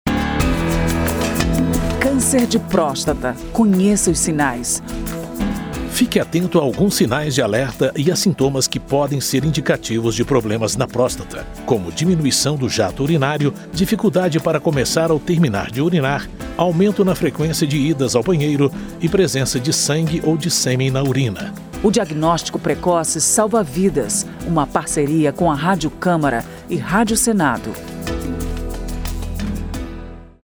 spot-cancer-de-prostata-02-parceiras.mp3